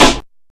Snare (28).wav